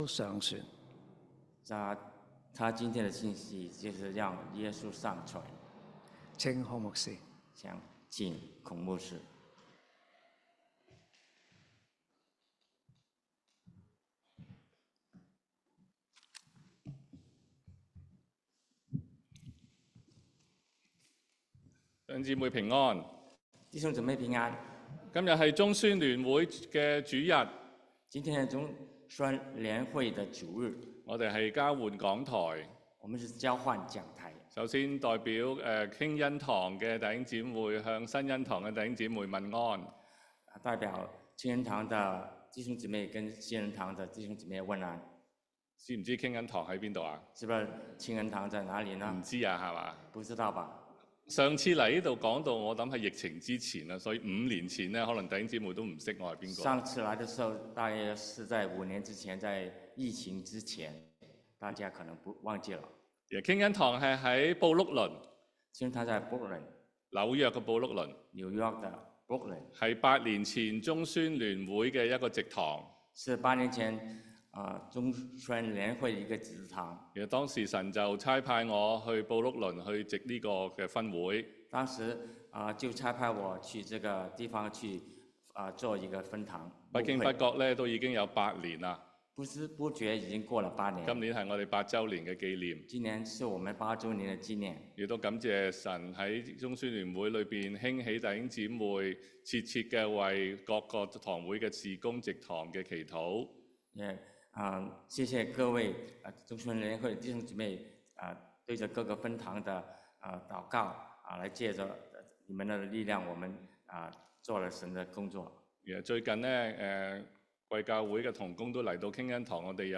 Video: Sunday English Worship Video Passcode: +iachFZ4 Micah 5:2-5a